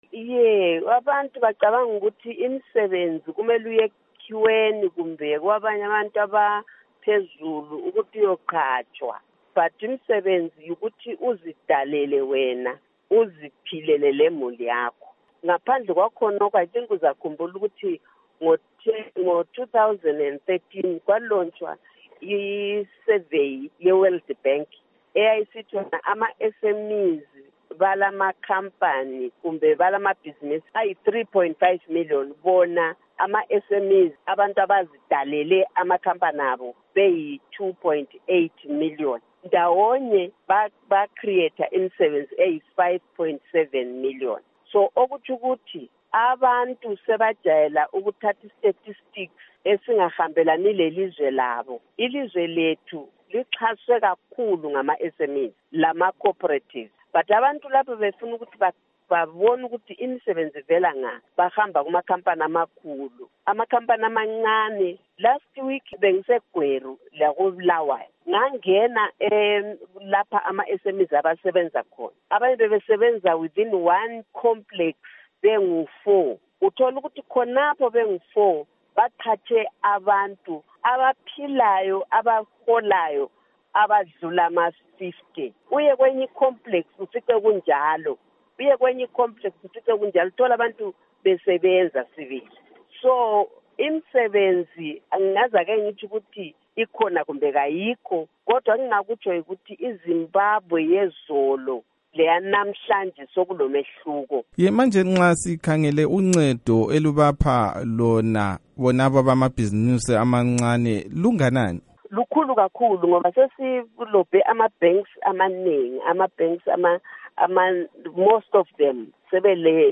Ingxoxo loNkosikazi Sithembiso Nyoni